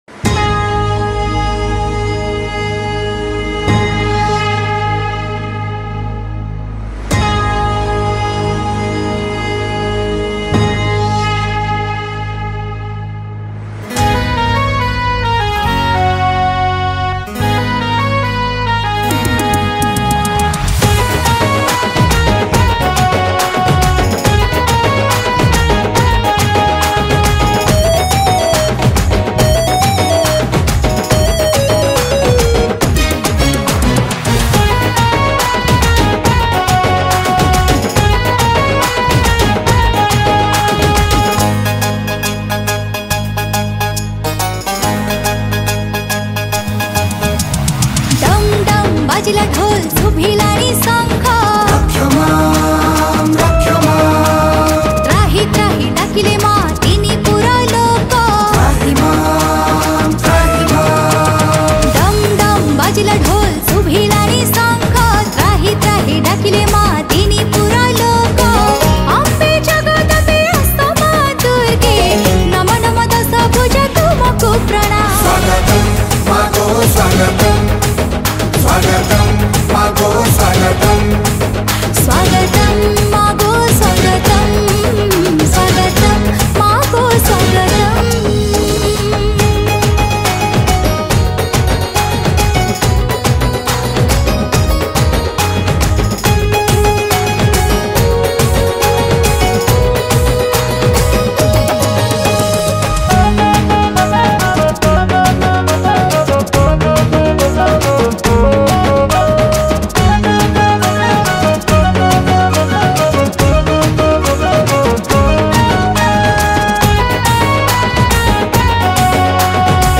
Durga Puja Special Song 2021 Songs Download
Keyboard
Drum
Studio Version